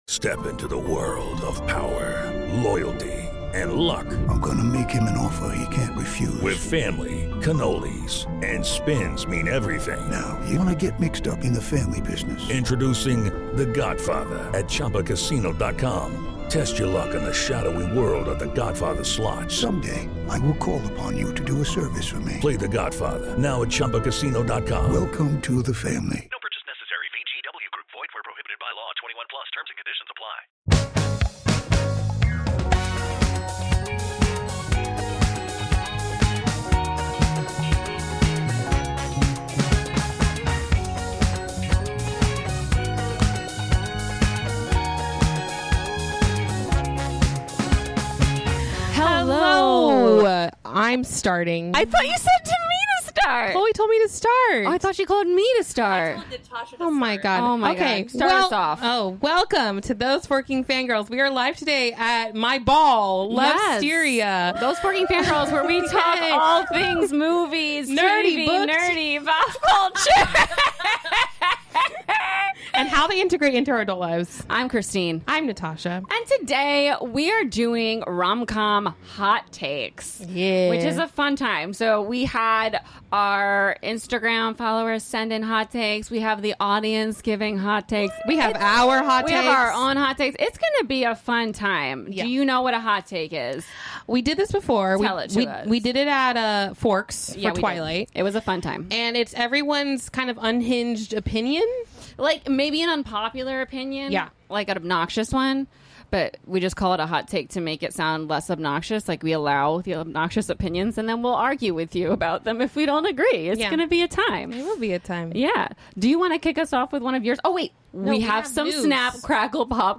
#79 | Reacting to Your Rom-Com Hottakes | Live from Lovesteria